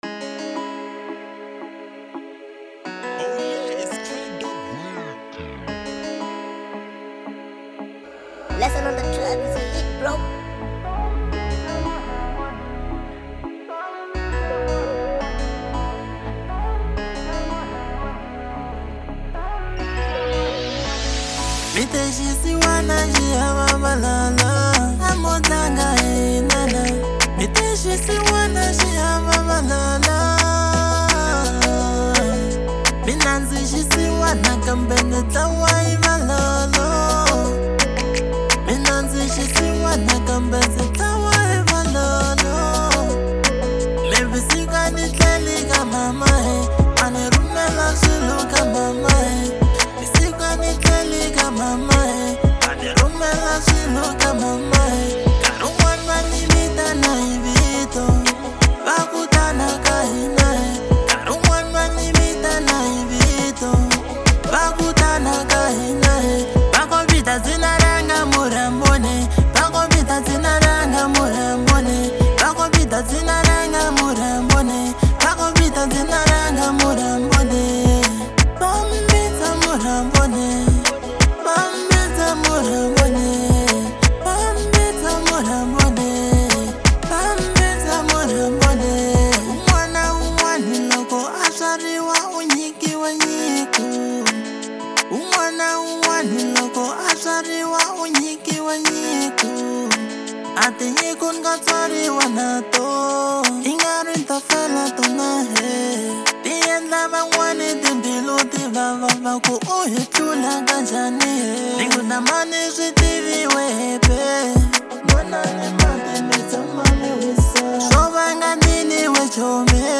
05:27 Genre : Afro Pop Size